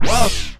VOCAL WHIZ.wav